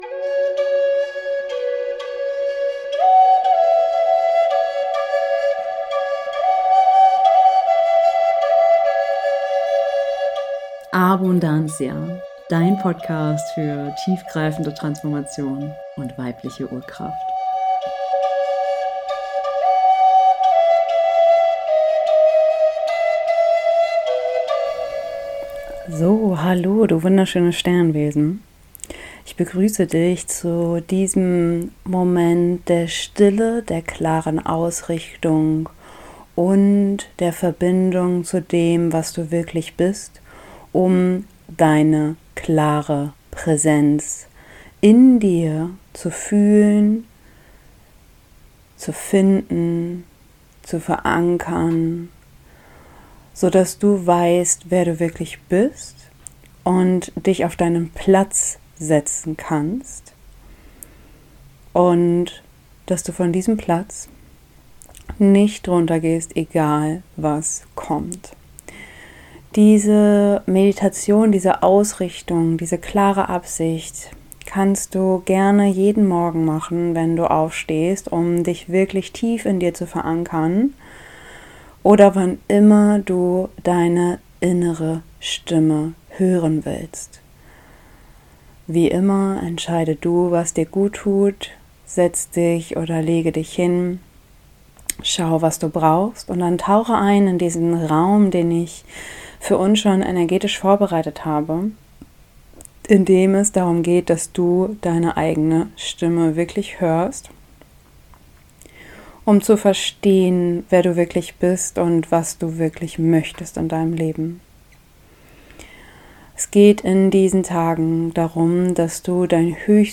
11# Tägliche Klarheit und Ausrichtung - Kurze Meditation für deinen Tag ~ ABUNDANCIA - Dein Podcast für weibliche Urkraft & tiefgreifende Transformation!
Für Wiederholerinnen: Die Meditation beginnt ab Minute 3:50.